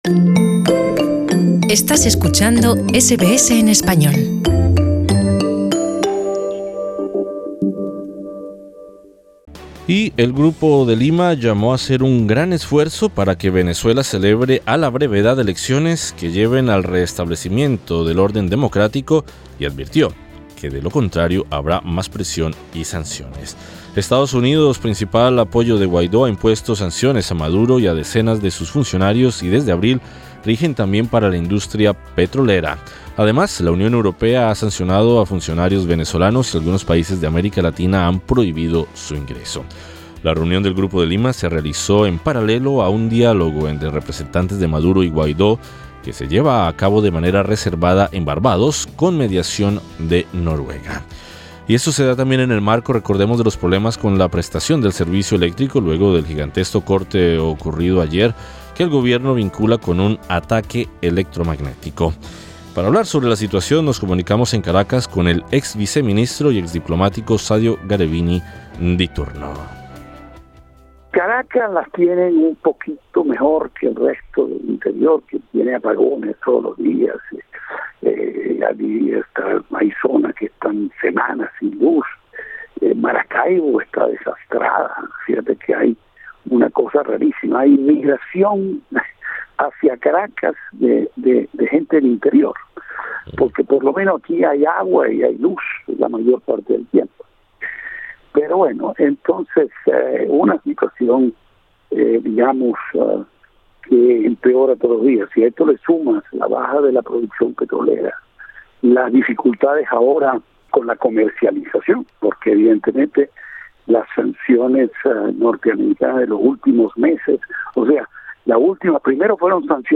Para hablar sobre la situación, nos comunicamos en Caracas con el ex viceministro y ex diplomático Sadio Garabini Di Turno.